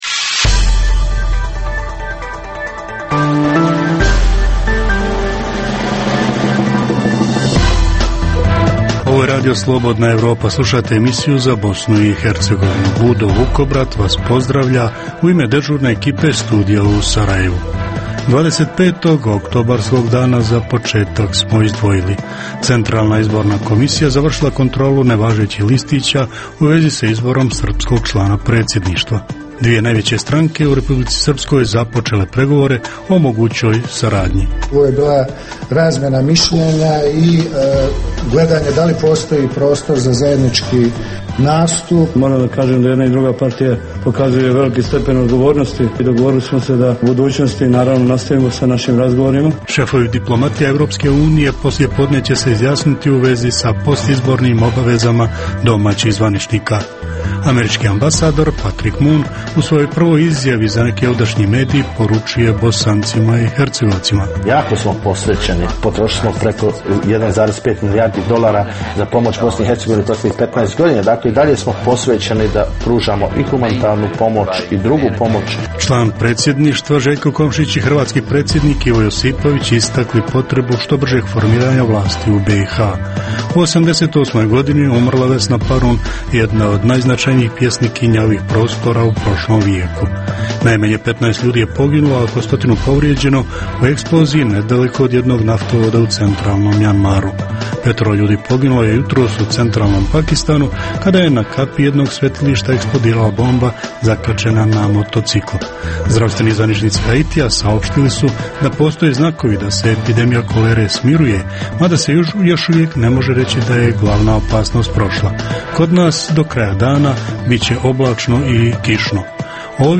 U emisiji za BiH - objavljujemo dijelove ekskluzivnog intervjua, prvog što ga je novi američki ambasador Patrik Mun dao za neki od ovdašnjih medija. Takođe, donosimo nove informacije u vezi sa očekivanjima međunarodnih zvaničnika od buduće vlasti, te rezultate prebrojavanja tzv. nevažećih glasova na oktobarskim izborima. Govorimo i o različitim oblicima diskriminacije i nasilja, računajući vjerske zajednice i žene.